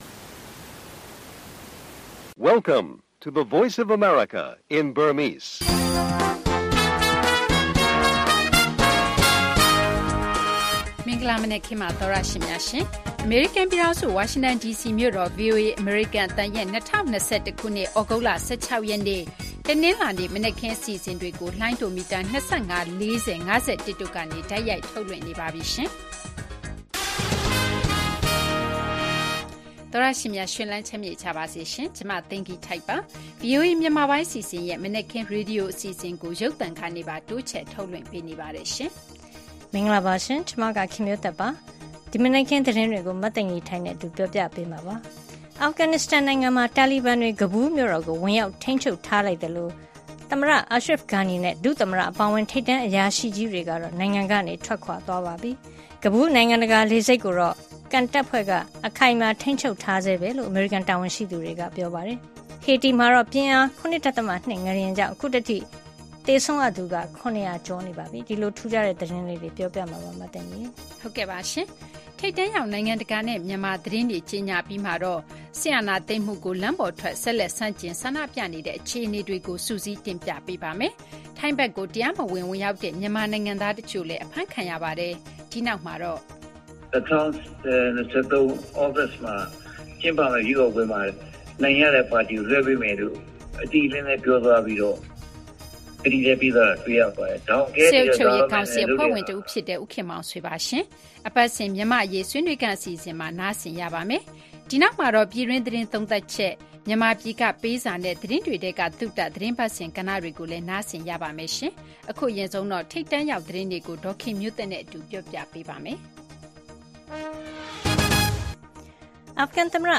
အာဖဂန် မြို့တော် ကာဘူးလ်ကို တာလီဘန်တို့ ဝင်ရောက် ထိန်းချုပ်မှု နောက်ဆုံး အခြေအနေ၊ မြန်မာ့ စစ်အာဏာသိမ်းမှု အပေါ် လမ်းပေါ်ထွက်ပြီး ဆက်လက် ဆန္ဒပြနေကြတဲ့ အကြောင်း၊ အိမ်စောင့်အစိုးရ ဖွဲ့တဲ့ အပေါ် စစ်ကောင်စီ အဖွဲ့ဝင် ဦးခင်မောင်ဆွေနဲ့ မေးမြန်းခန်း အပြင် ပြည်တွင်းသတင်းသုံးသပ်ချက်၊ မြန်မာပြည်က ပေးစာနဲ့ သတင်းတွေထဲက သုတ အပတ်စဉ် ကဏ္ဍတွေကို နားဆင်ကြရပါမယ်။